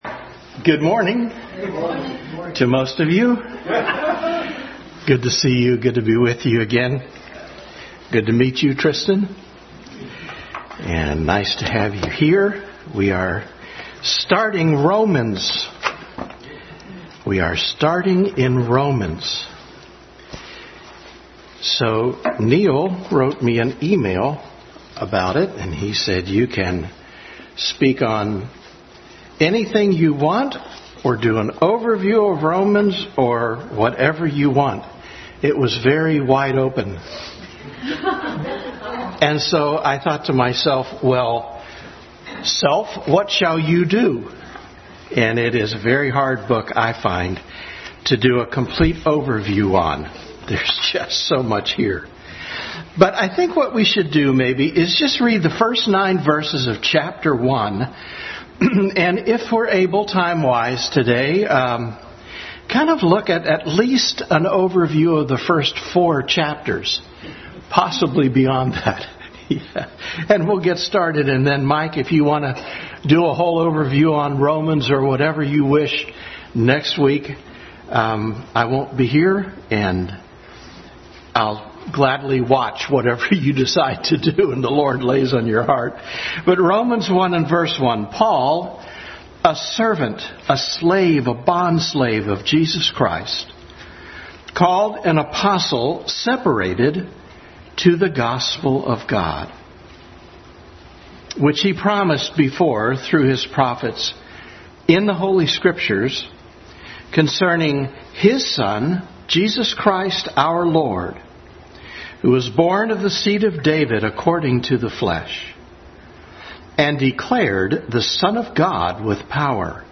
Adult Sunday School Class introduction to a study of the book of Romans.
3:23-28 Service Type: Sunday School Adult Sunday School Class introduction to a study of the book of Romans.